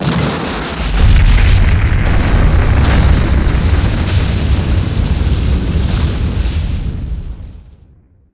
1 channel
Buildingblast4.wav